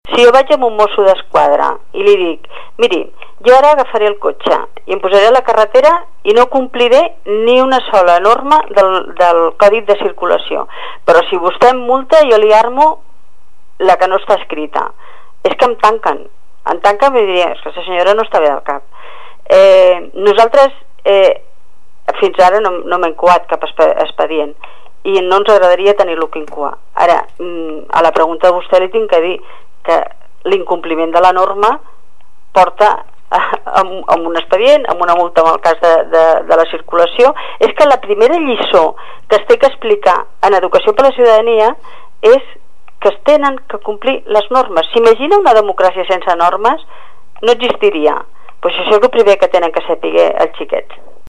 Educació admet les dificultats de molts professors per a ensenyar ciutadania en anglès, però és inflexible a l’hora d’expedientar els qui desobeeixin l’ordre, com va explicar a VilaWeb la secretària d’Educació, Concha Gómez (